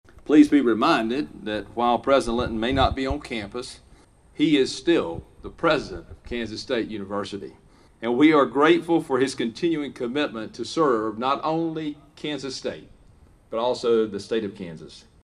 A community gathering was held outside Anderson Hall Tuesday evening as state, local and faith leaders came together in support of Kansas State University President Richard Linton, who earlier in the day announced his diagnosis of throat and tongue cancer.